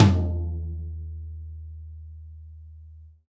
Index of /kb6/Akai_MPC500/1. Kits/Amb Rm Kit